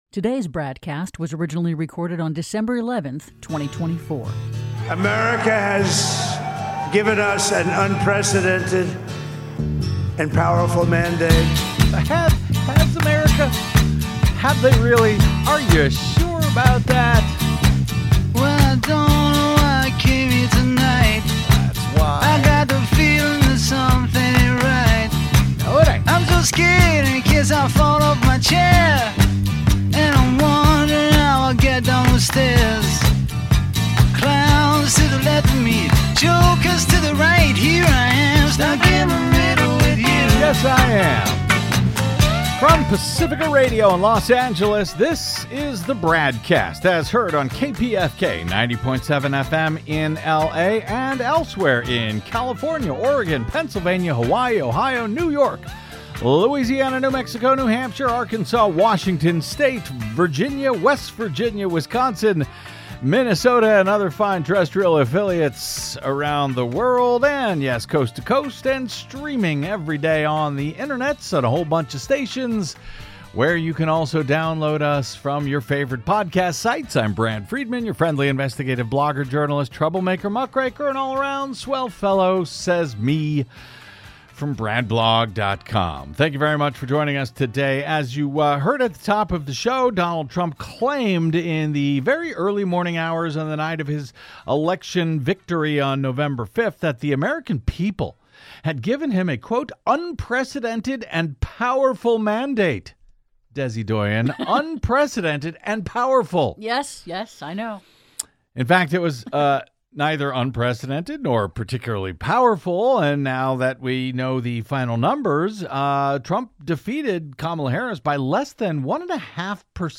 Originating on Pacifica Radio's KPFK 90.7FM in Los Angeles and syndicated coast-to-coast and around the globe!